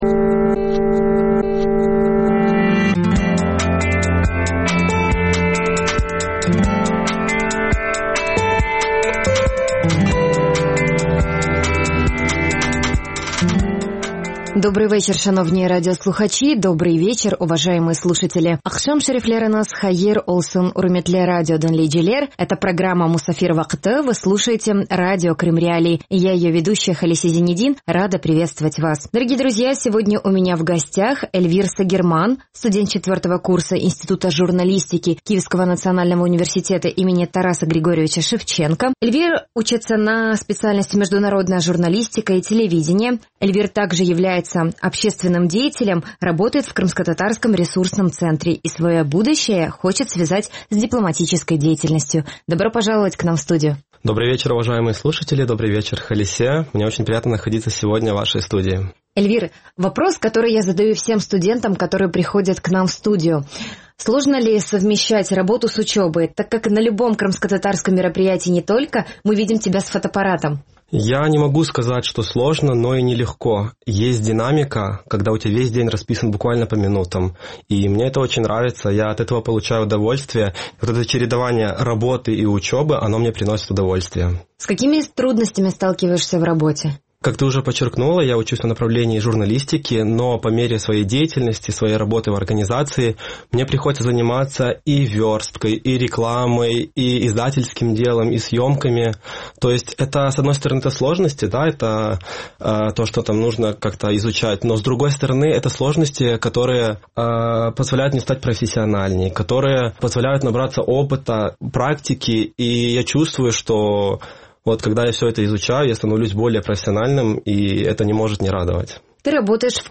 Програма звучить в ефірі Радіо Крим.Реалії. Час ефіру: 18:40 – 18:50 (19:40 – 19:50 у Криму).